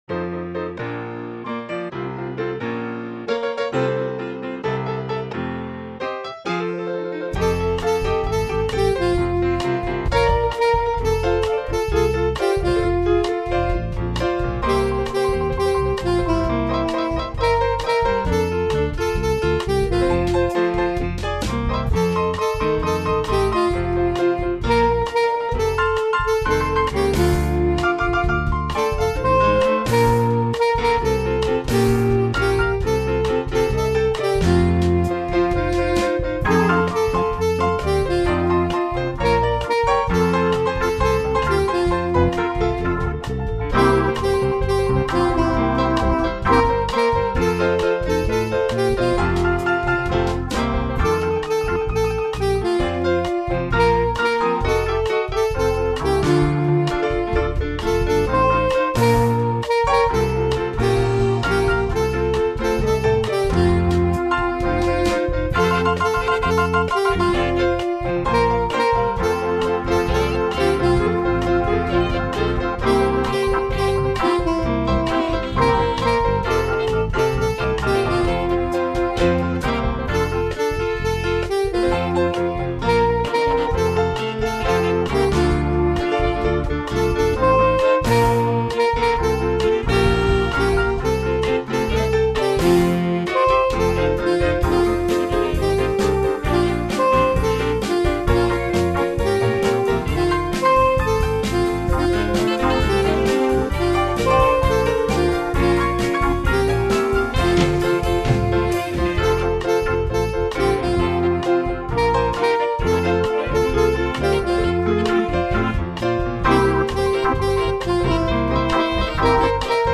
in F
with pretty simple chords